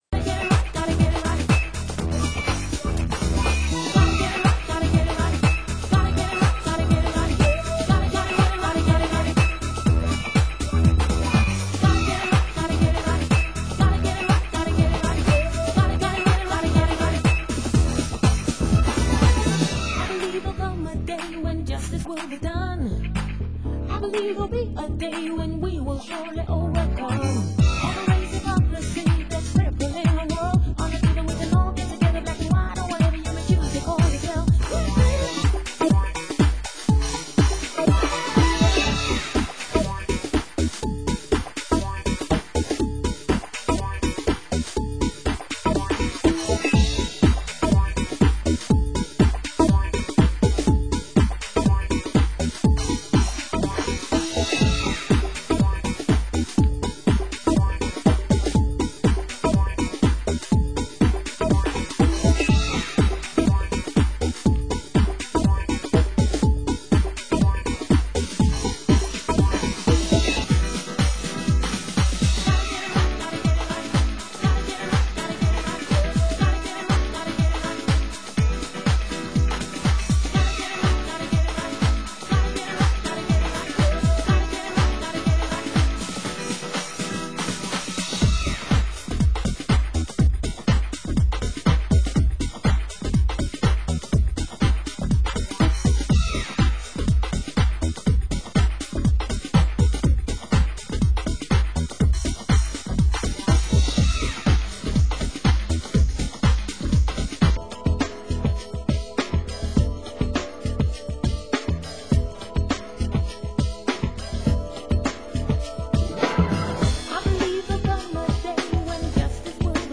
Genre: UK House
B2 Extended Club Mix